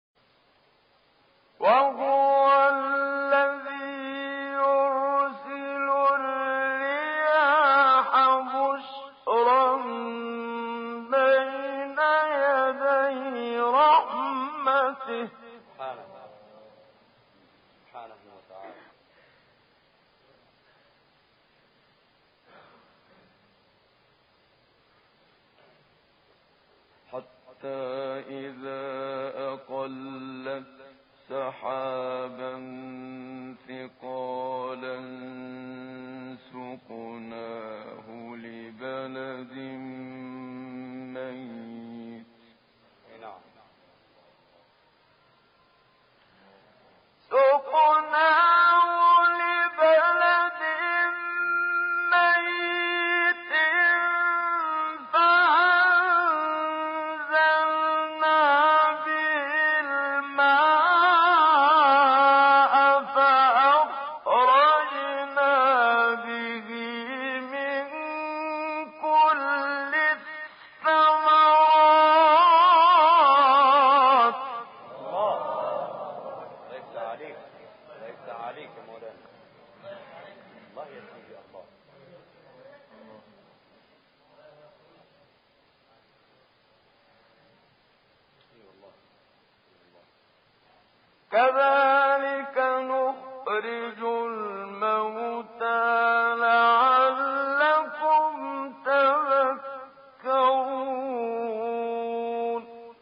تلاوت سوره اعراف با صوت «محمدصدیق منشاوی»
گروه شبکه اجتماعی ــ تلاوت آیاتی از سوره‌ مبارکه اعراف که شامل آیه‌های بهاری است، با صوت محمدصدیق منشاوی می‌شنوید.
محمدصدیق منشاوی در این تلاوت که در کشور لیبی اجرا شده، آیات 42 تا 74 سوره مبارکه اعراف را قرائت کرده است.